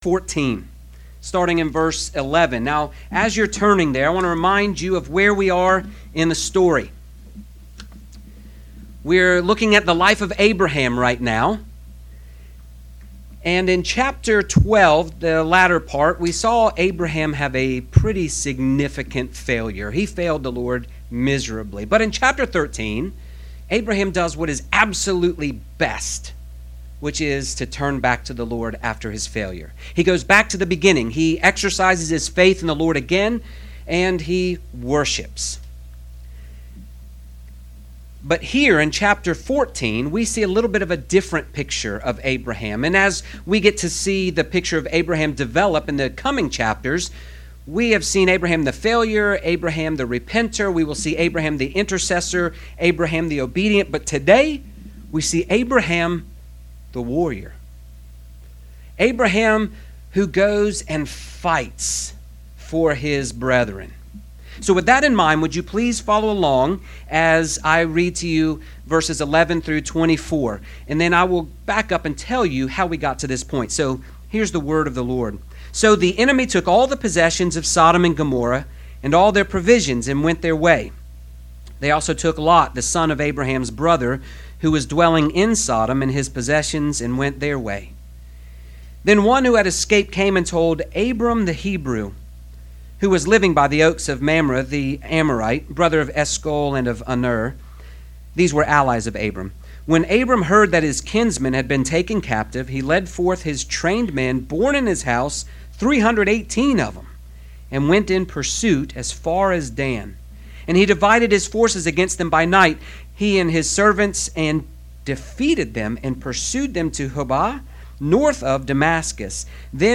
Grace Presbyterian Church, PCA Sermons